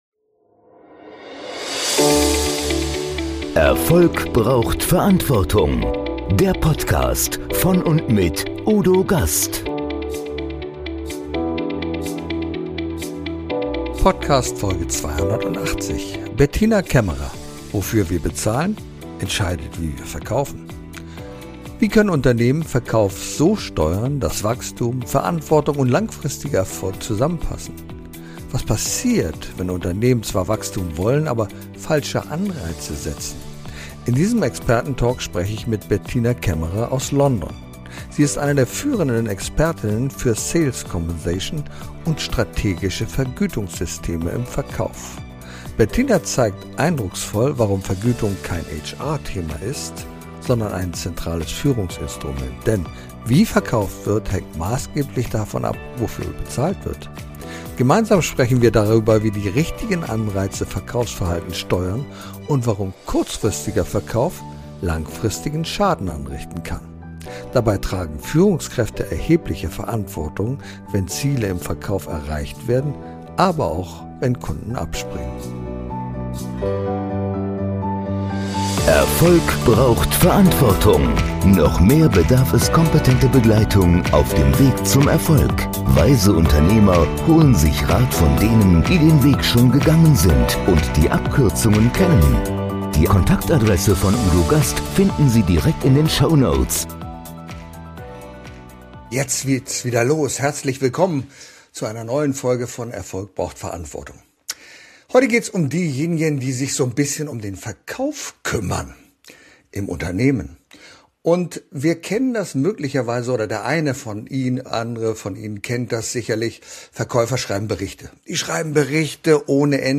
Experten Talk